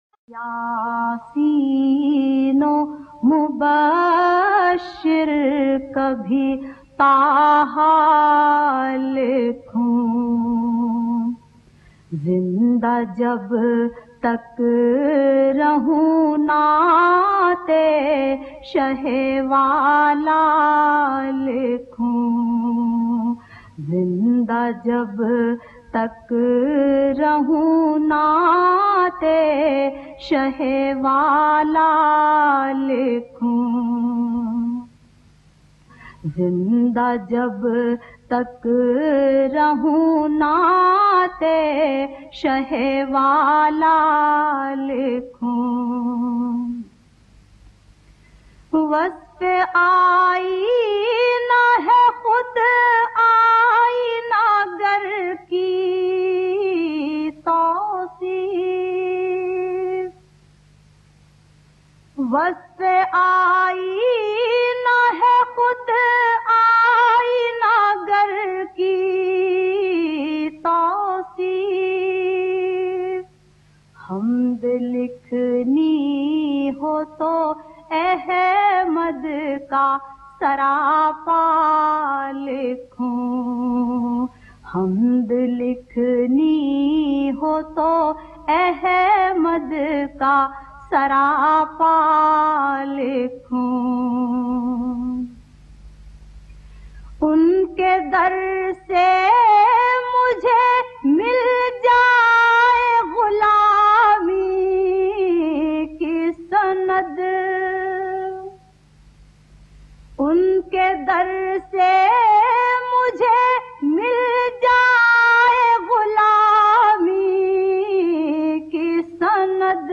She has her very own style of reciting Naats.